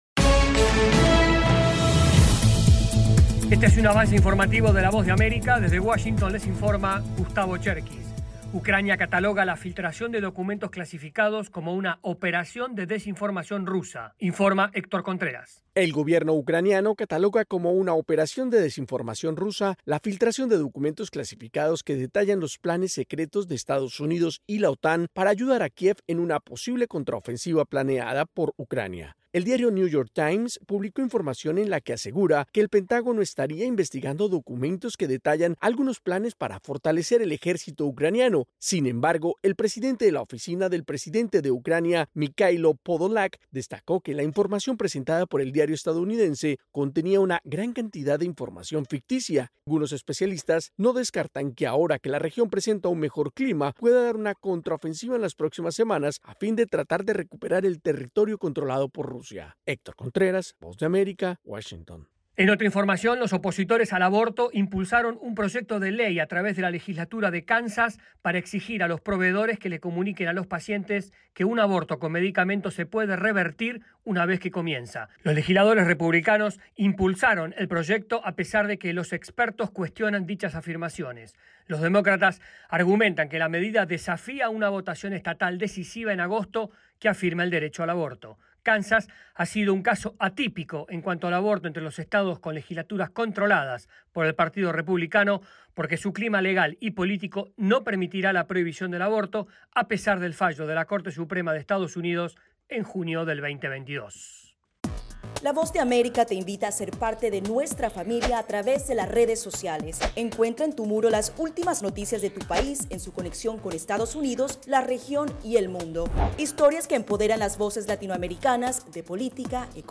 Avance Informativo
Este es un avance informativo de la Voz de América.